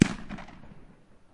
爆炸 " 009 烟花
Tag: 有声 轰的一声 烟花 爆竹 爆炸